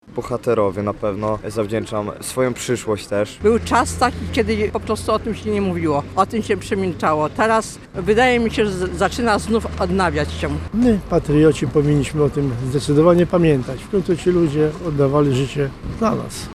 Nasz reporter rozmawiał również z osobami zgromadzonymi przed pomnikiem podczas uroczystości: